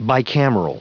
Prononciation du mot bicameral en anglais (fichier audio)
bicameral.wav